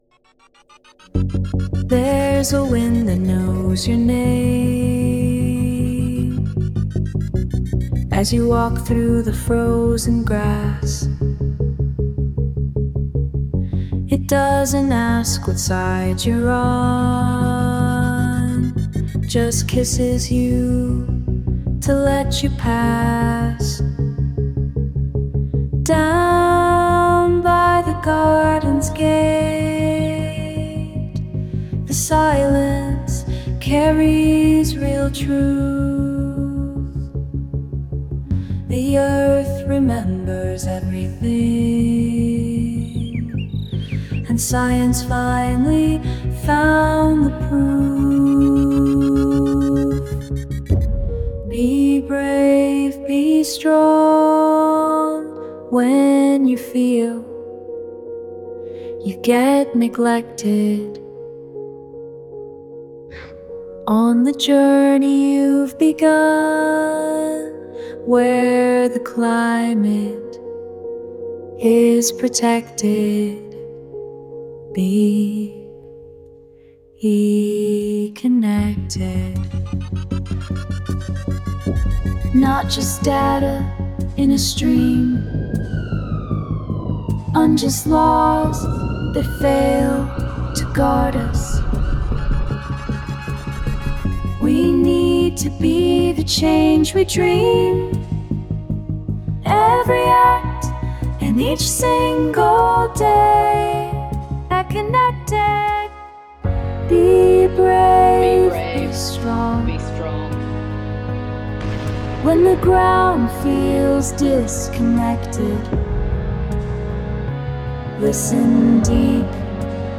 – A tender call to reawaken our climate courage and feel nature’s voice – to be ‘eco-nnected‘.